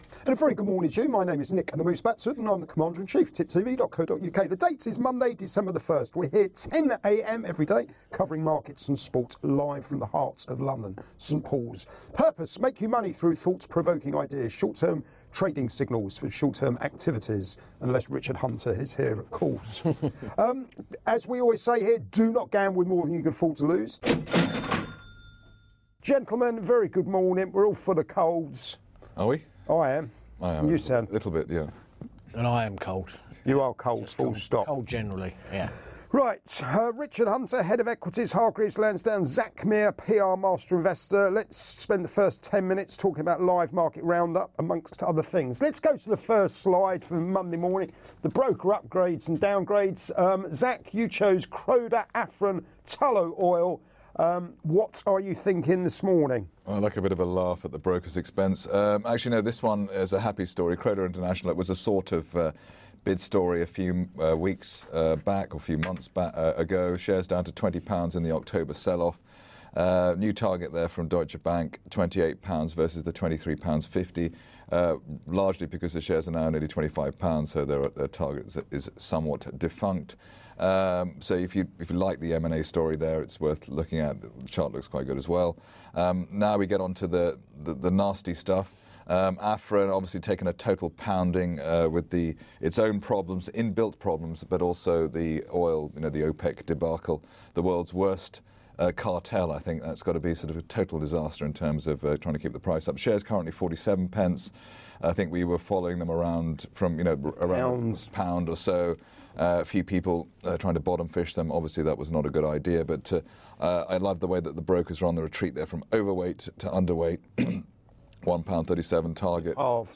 Live Market Round-Up & Soapbox thoughts